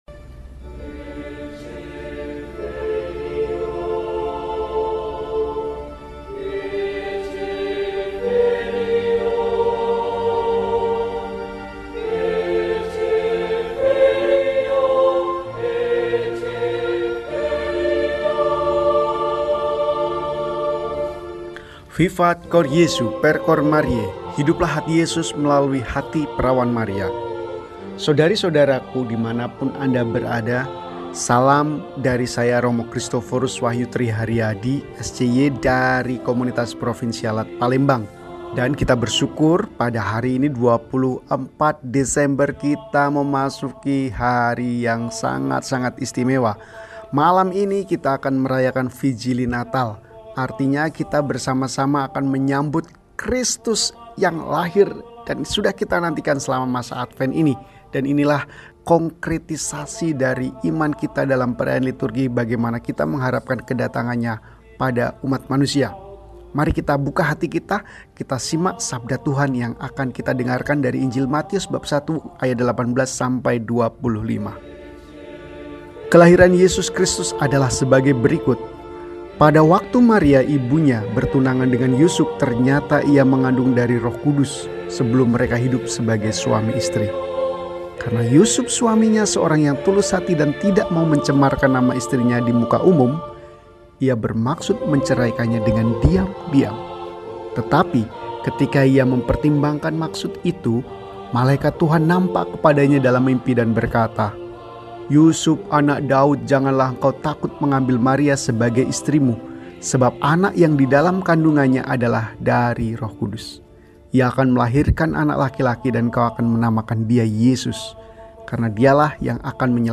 Selasa, 24 Desember 2024 – Malam Natal – RESI (Renungan Singkat) DEHONIAN